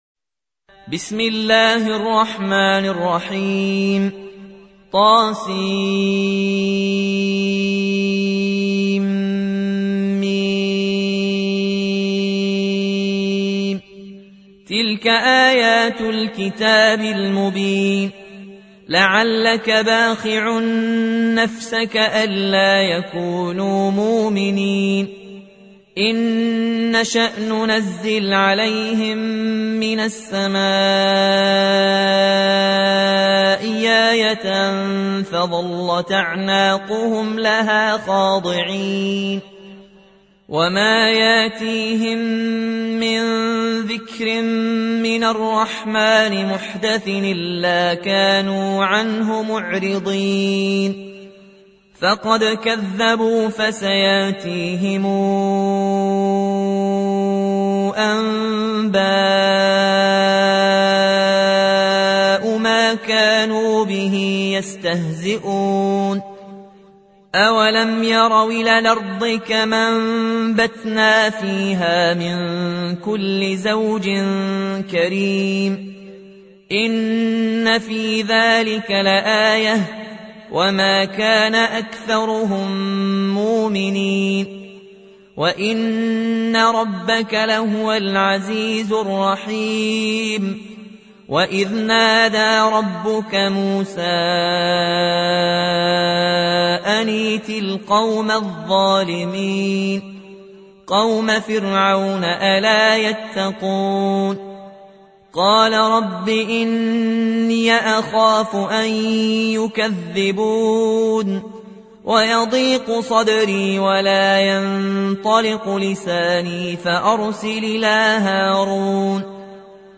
سورة الشعراء | القارئ